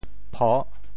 How to say the words 抱 in Teochew？
pho6.mp3